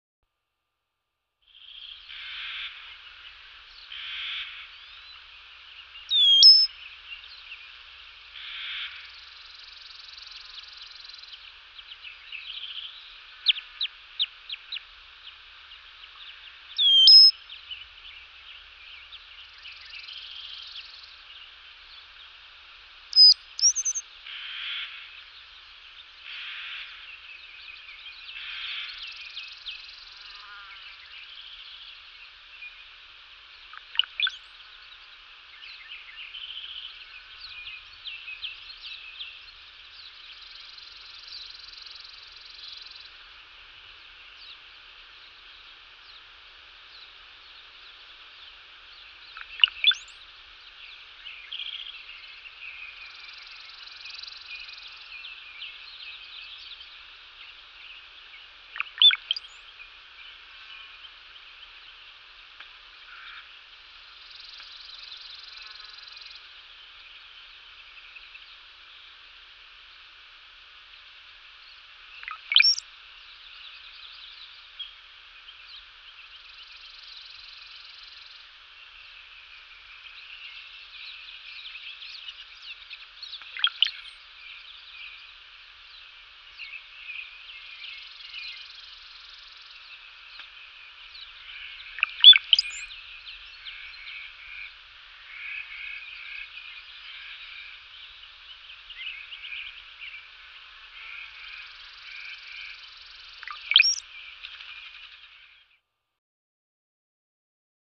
Cowbird, Brown-headed Tweets, Chirps. High-pitched Tweets With Droplet-like Chirps. Outdoor Ambience With Distant Birds And Insects. Medium Perspective.